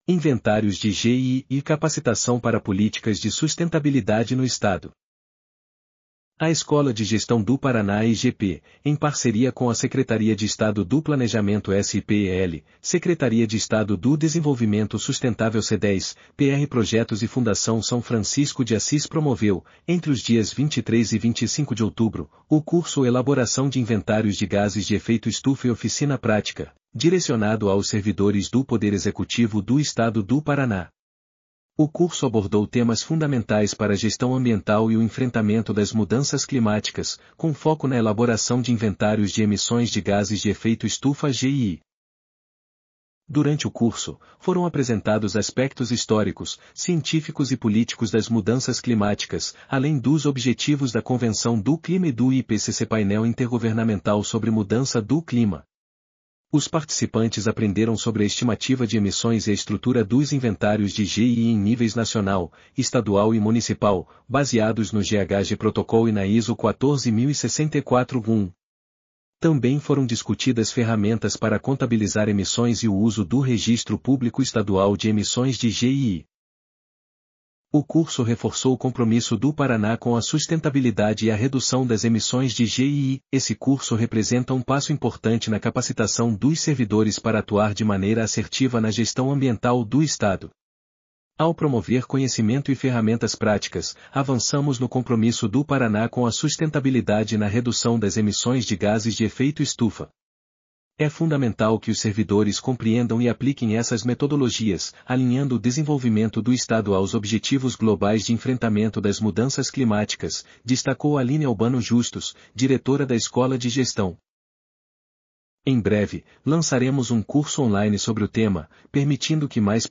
audionoticia_inventarios_de_gee.mp3